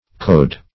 Code \Code\ (k[=o]d), v. t.